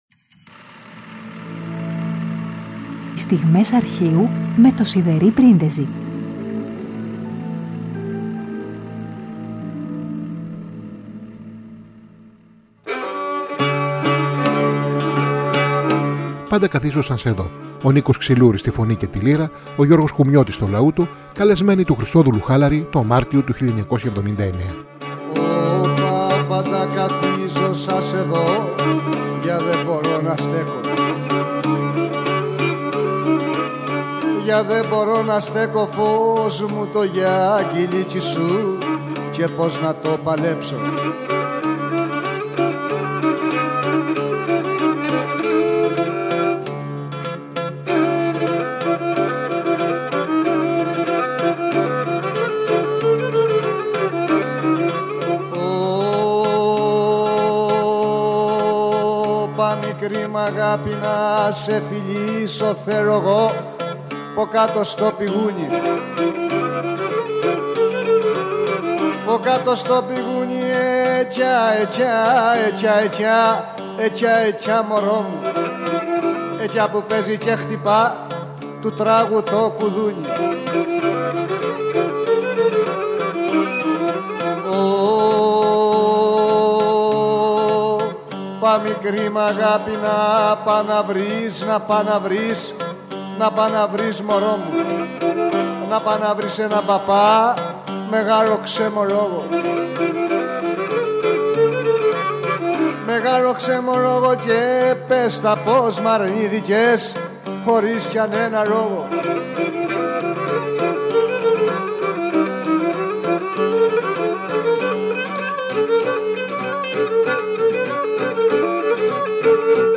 στη φωνή και τη λύρα
στο λαούτο